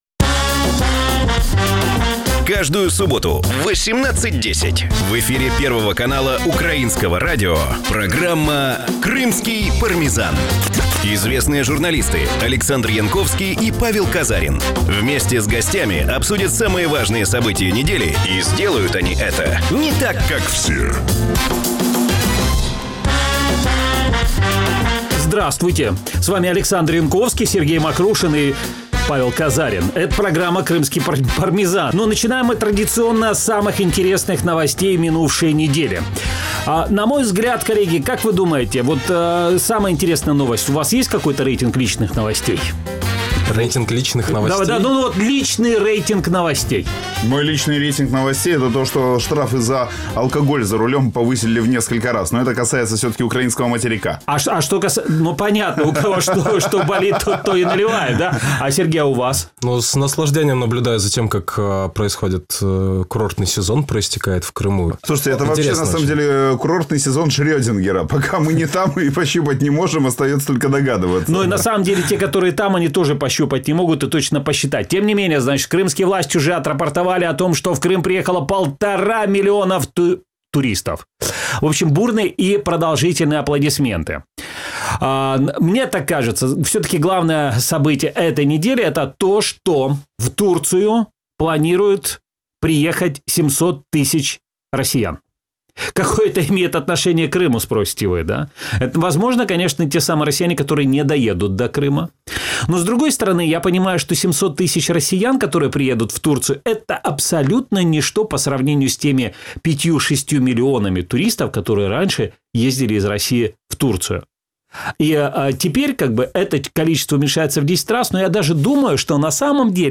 Какое будущее ждет крымчан с законами Яровой? Стал ли Владимир Путин их первой жертвой или же он исчез в поисках российских резервов – ответы на эти вопросы в нашей программе. Программа звучит в эфире Радио Крым.Реалии. Это новый, особенный формат радио.